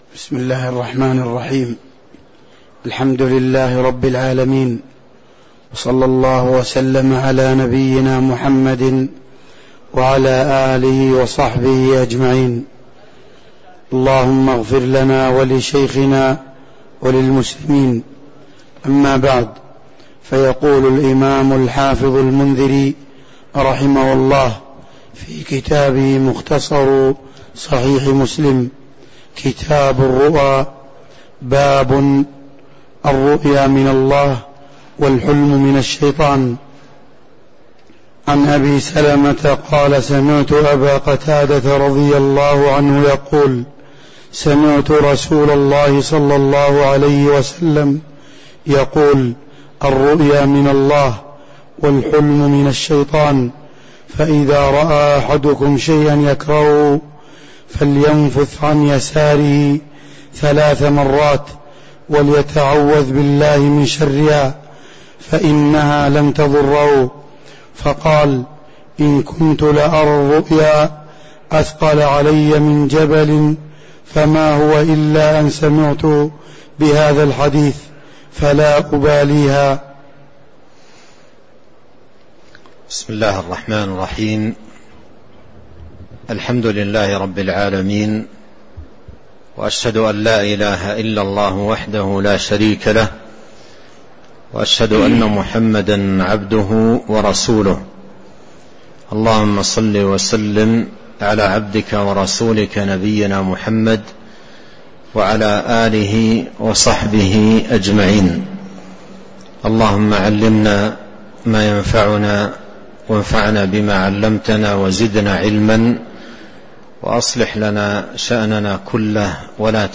تاريخ النشر ٣ شعبان ١٤٤٣ هـ المكان: المسجد النبوي الشيخ